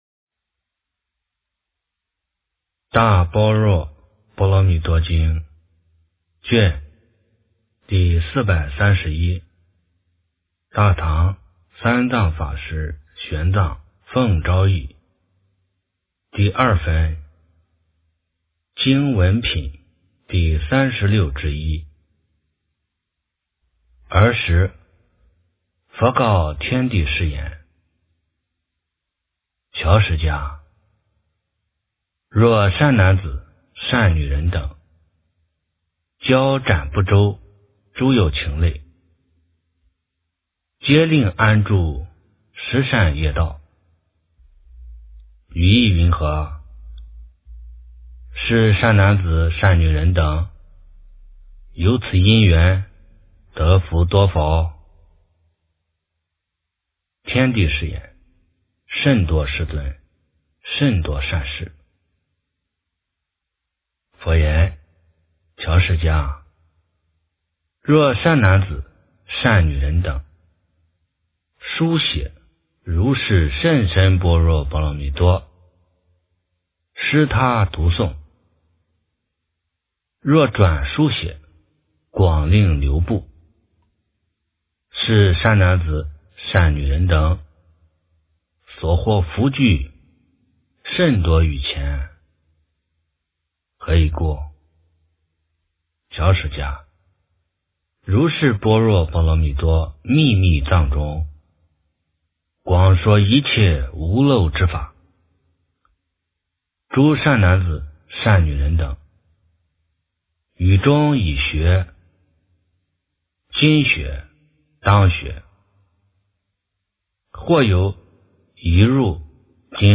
大般若波罗蜜多经第431卷 - 诵经 - 云佛论坛